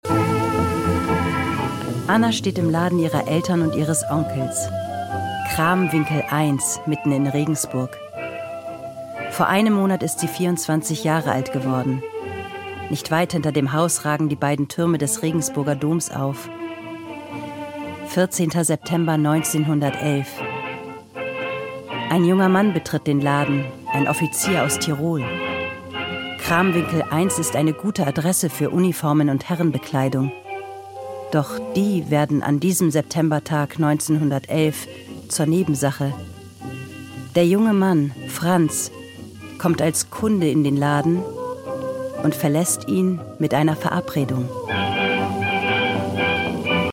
sehr variabel
Mittel minus (25-45)
Norddeutsch
Audioguide, Doku